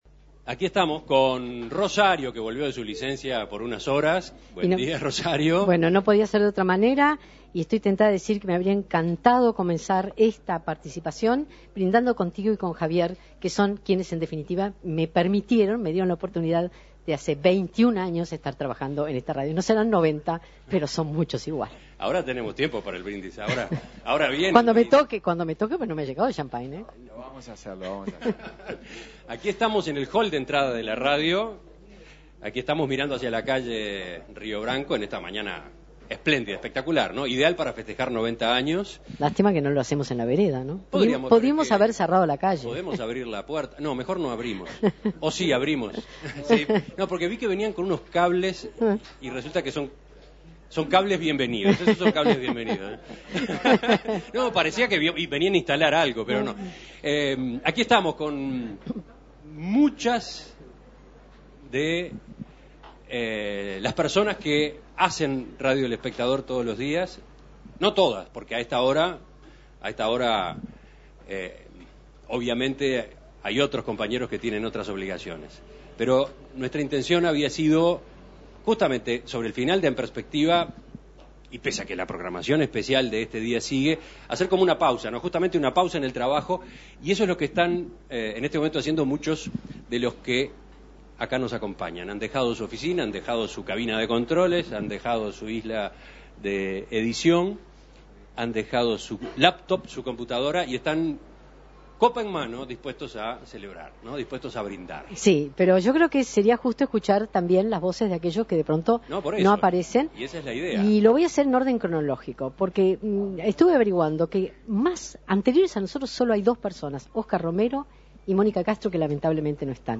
El micrófono se instaló en planta baja para encontrarse con quienes estaban trabajando en distintas secciones de la radio en el pasado viernes 13 de diciembre
Salimos del estudio principal para poder descubrir sus voces y brindar con ellos, todos juntos, por los 100 años de la radio, que son nuestra próxima meta.